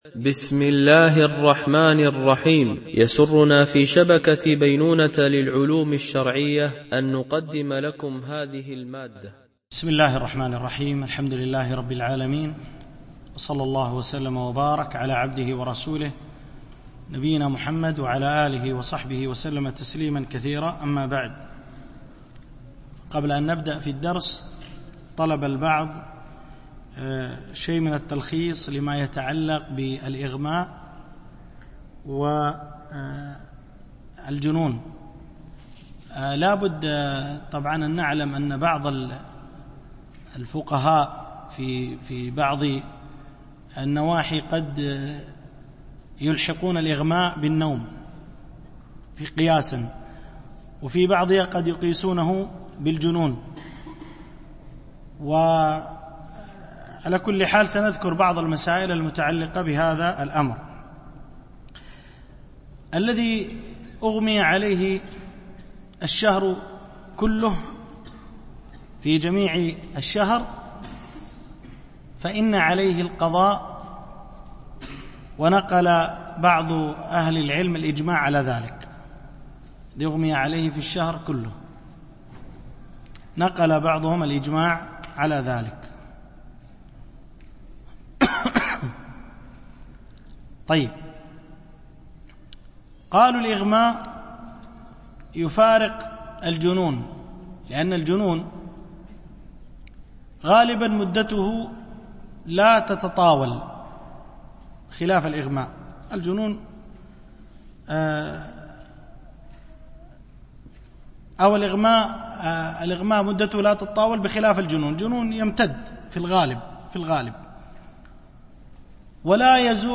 الدورة: دورة علمية شرعية، بمسجد أم المؤمنين عائشة - دبي (القوز 4)